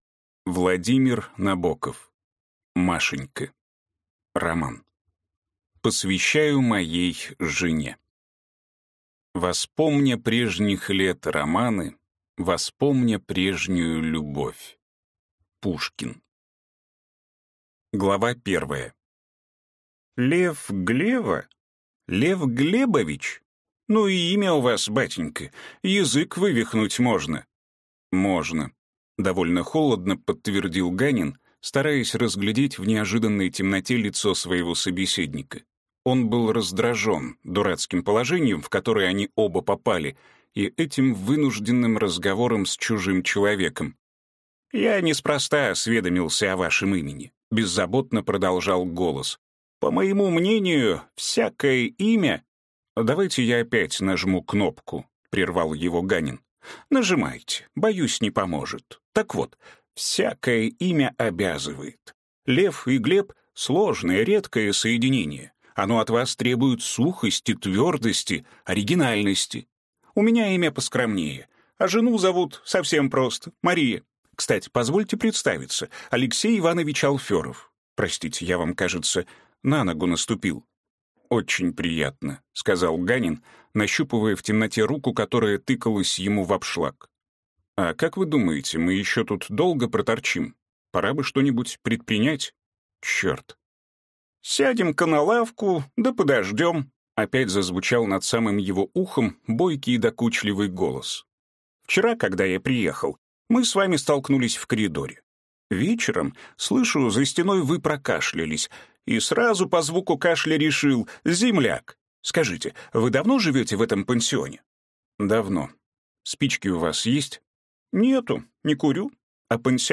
Аудиокнига Машенька | Библиотека аудиокниг